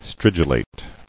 [strid·u·late]